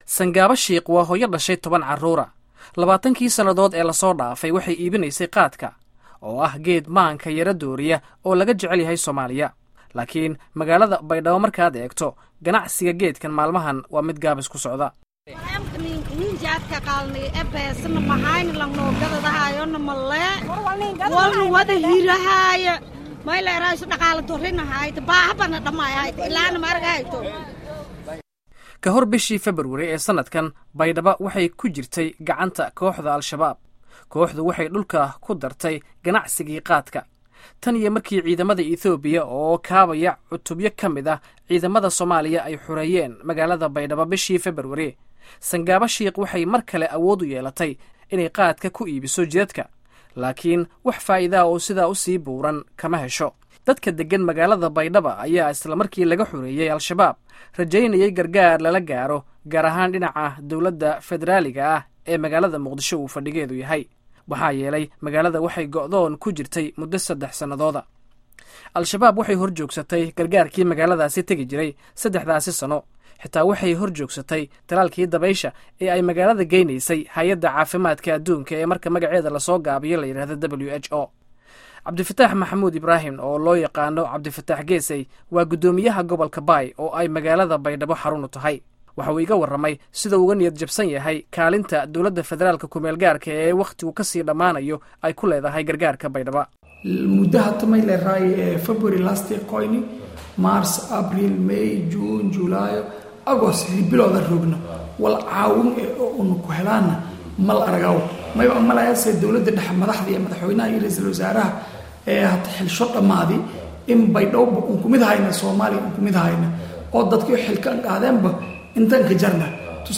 Warbixinta Xaaladda Baydhaba halkan ka dhageyso